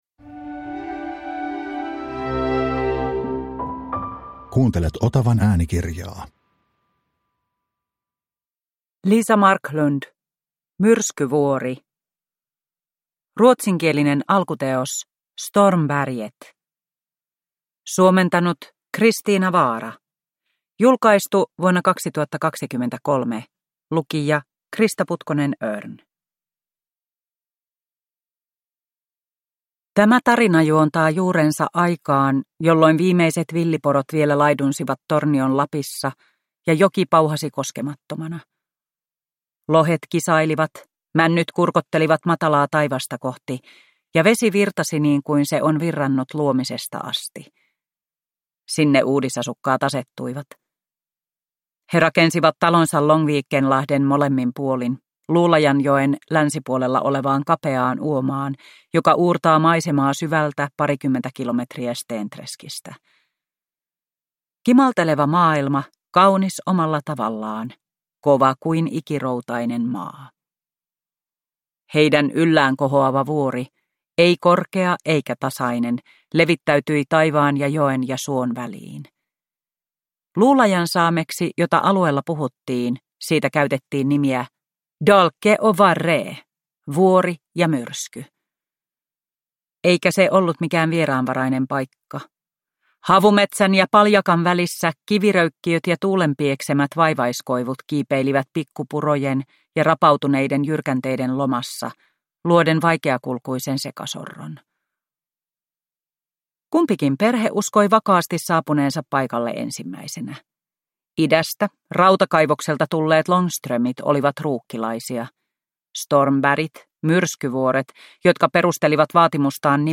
Myrskyvuori – Ljudbok – Laddas ner